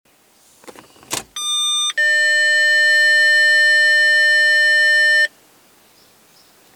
Rückwärtsgang piepst doppelt - Forum: Stream
Mein Honda Stream (RN 2.0 Automatik) pipst beim Einlegen des Rückwärtsgangs kurz - das ist normal. Seit kurzem hat er ein zweites Piepsen in einer tieferen Tonart, das unterschiedlich lange anhält.
Piep-Rueckwaertsgang.mp3